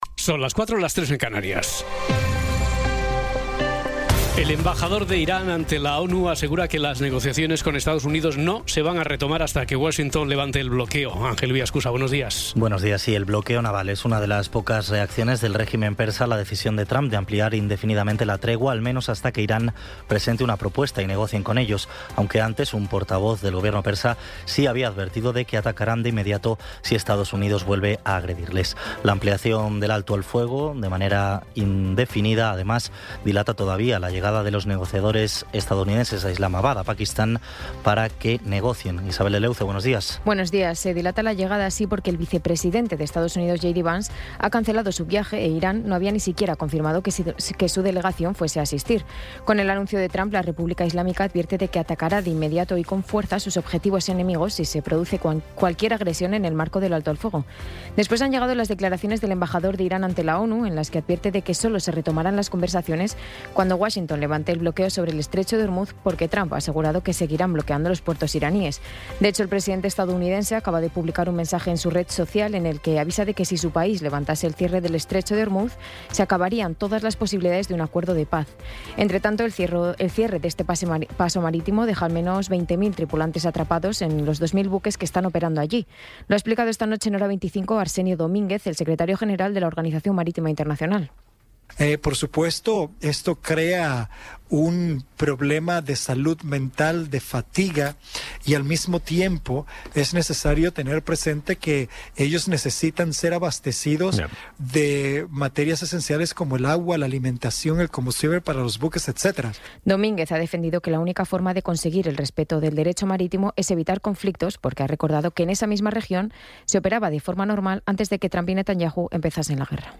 Resumen informativo con las noticias más destacadas del 22 de abril de 2026 a las cuatro de la mañana.